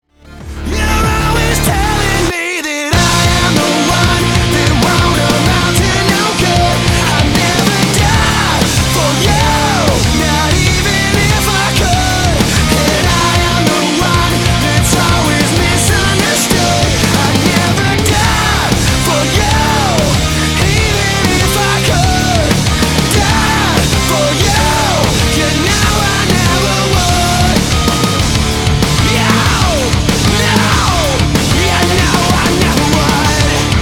• Качество: 320, Stereo
мужской вокал
громкие
мотивирующие
электрогитара
nu metal
Hard rock
Альтернативный рок